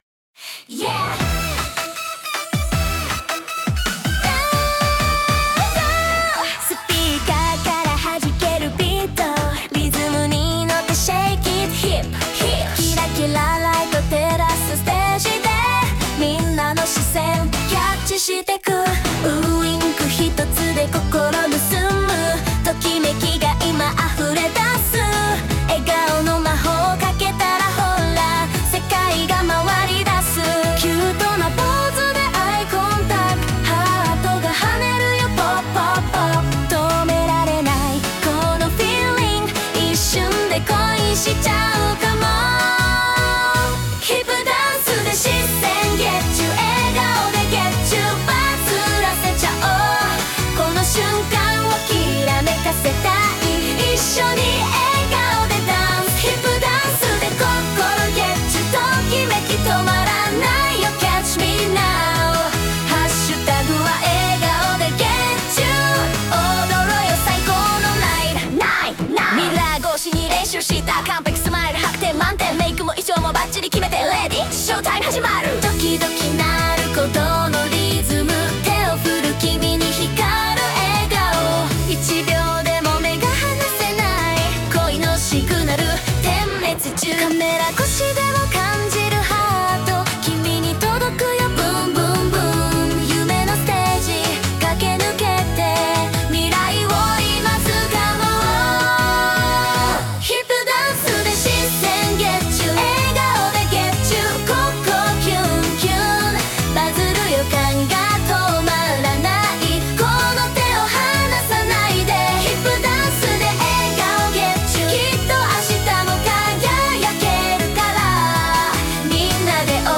ステージのきらめきを感じるアイドルチューン